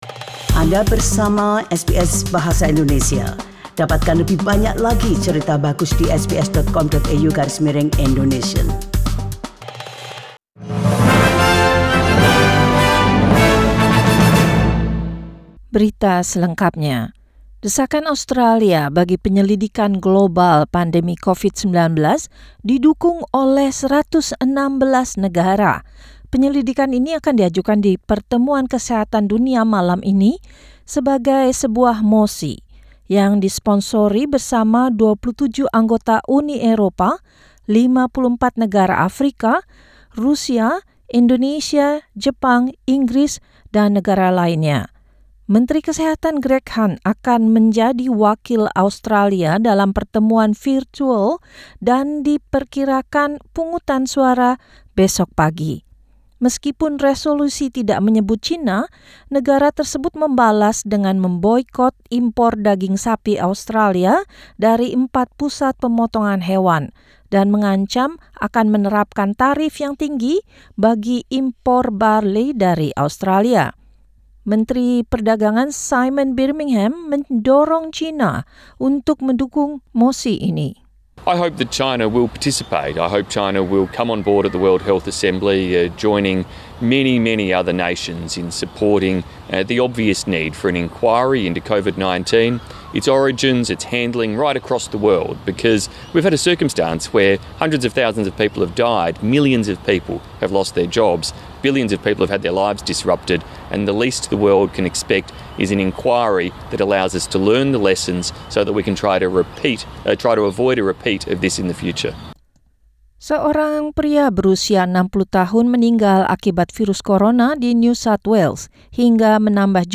SBS Radio News in Indonesian 18 May 2020